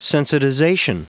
Prononciation du mot sensitization en anglais (fichier audio)
Prononciation du mot : sensitization